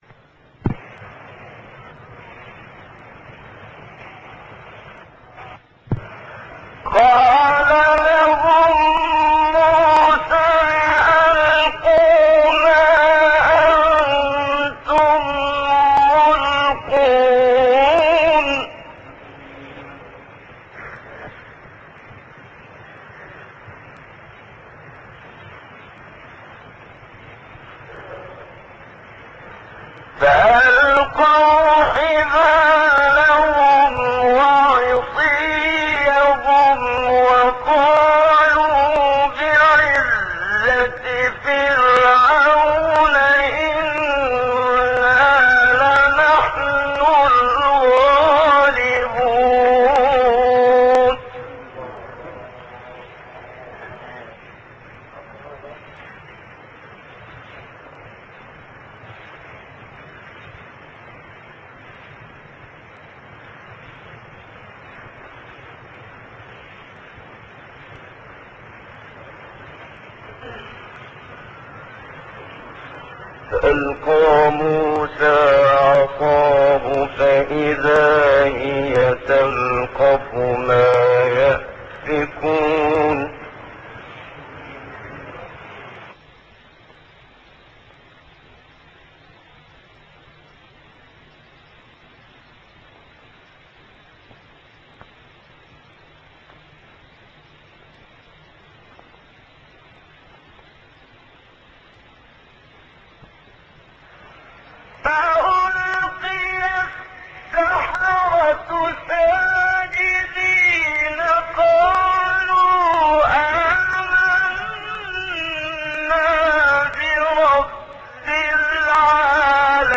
سایت-قرآن-کلام-نورانی-منشاوی-چهار-گاه.mp3